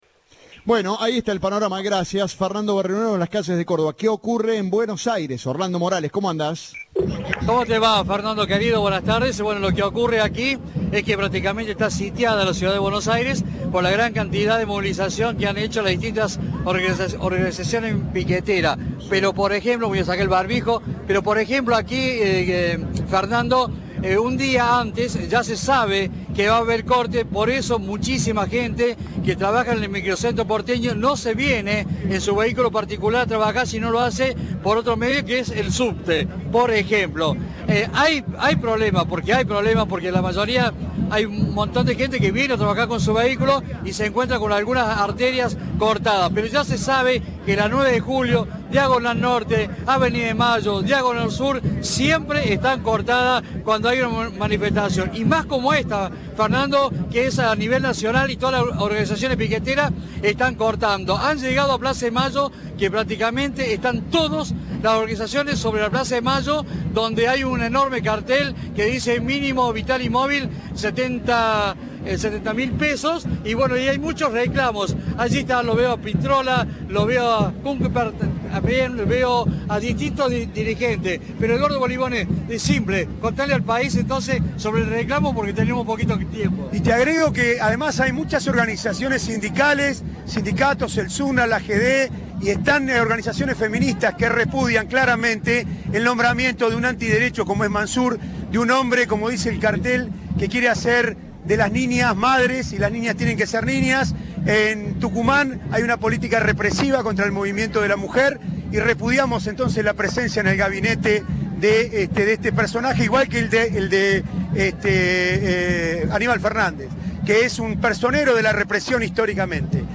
dijo a Cadena 3 desde la manifestación en Buenos Aires
Informe